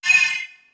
menu_close.wav